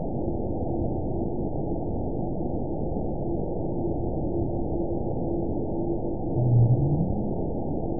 event 922852 date 04/19/25 time 22:58:47 GMT (1 week, 4 days ago) score 9.26 location TSS-AB02 detected by nrw target species NRW annotations +NRW Spectrogram: Frequency (kHz) vs. Time (s) audio not available .wav